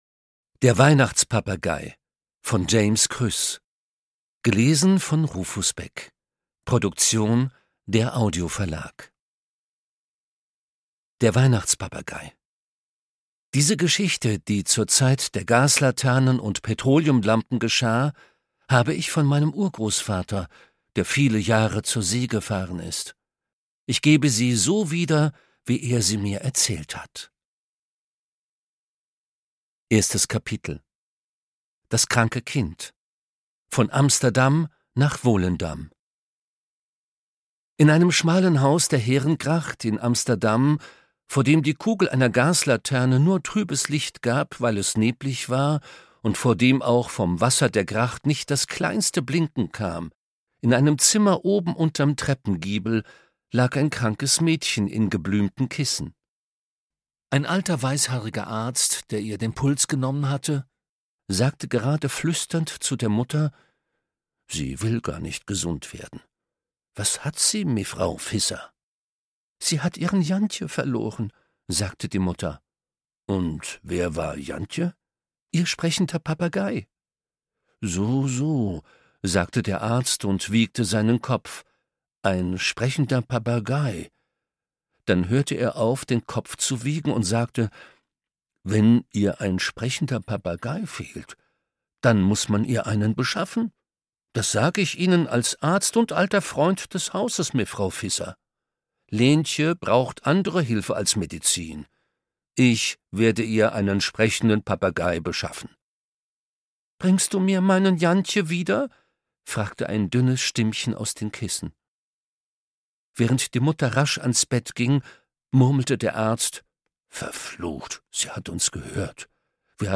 Ungekürzte Lesung mit Rufus Beck (2 CDs)
Rufus Beck (Sprecher)
Rufus Beck liest das zauberhafte Weihnachtsabenteuer von James Krüss.